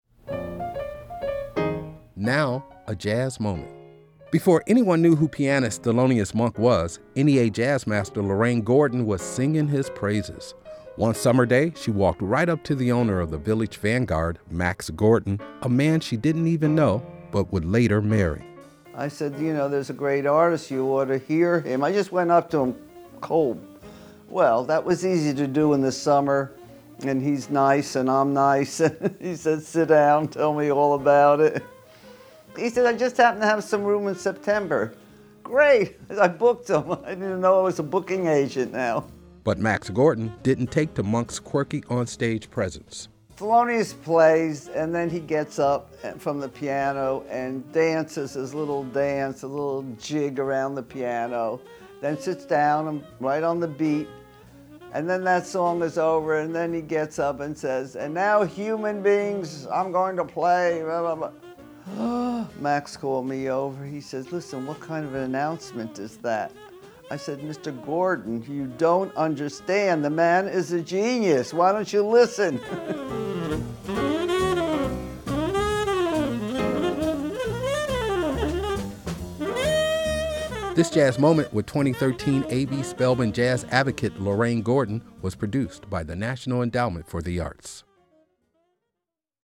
But as an early champion of the piano player, Lorraine Gordon had to use her powers of persuasion to secure him his first gig at the Village Vanguard. [1:29] Excerpt of “Blues Five Spot” composed and performed by Thelonious Monk from Misterioso, used by courtesy of Concord Music Group and by permission of Thelonious Music Corp (BMI)